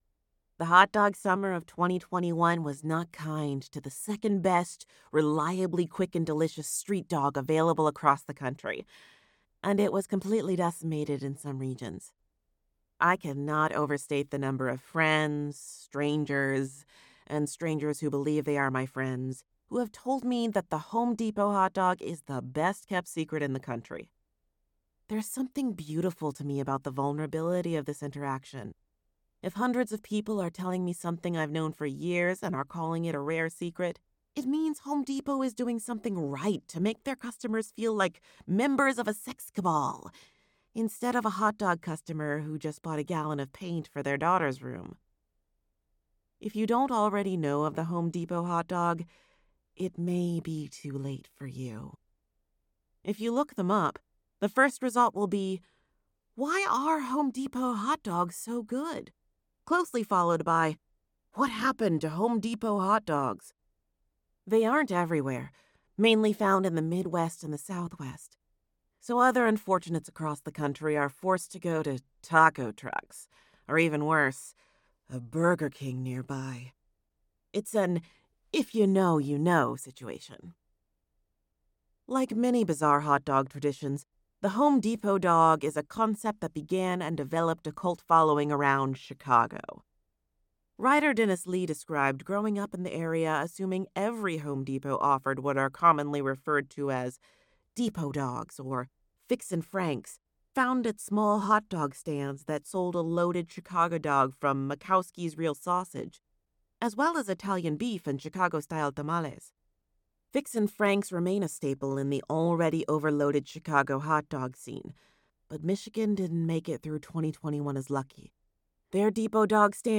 Nonfiction Sample